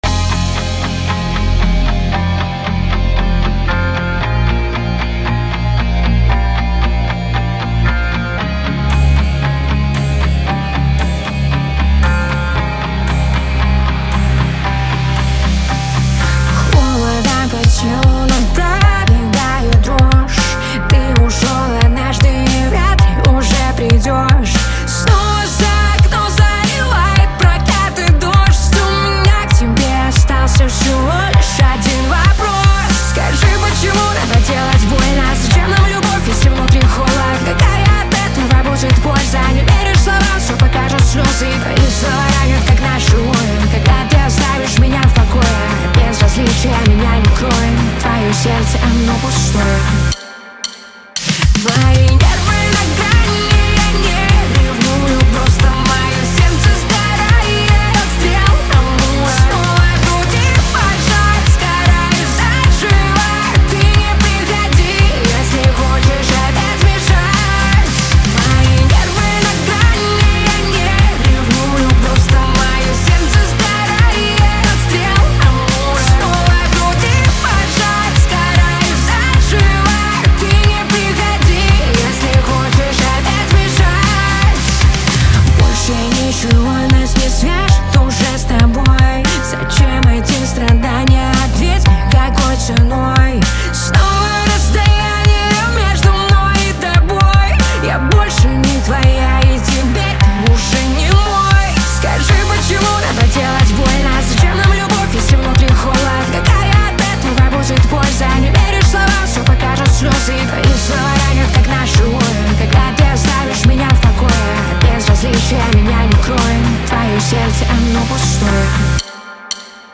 Rock Cover